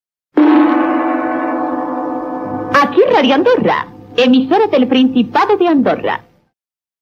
Indicatiu - Radio Andorra, 1960's